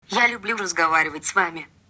Звуки Siri